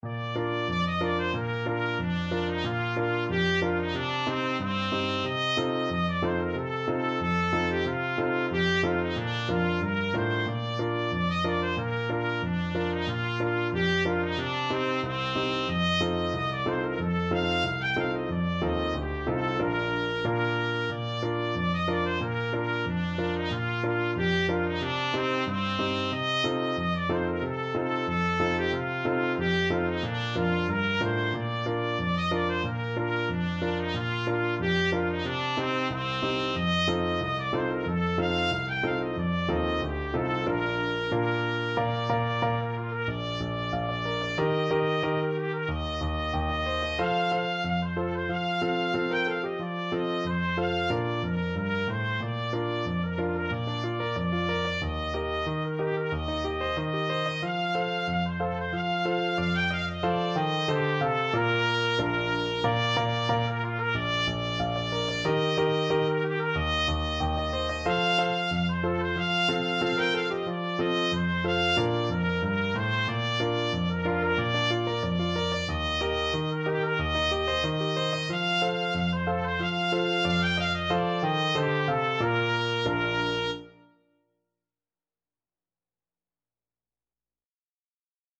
Traditional Music of unknown author.
Moderato =c.92
2/4 (View more 2/4 Music)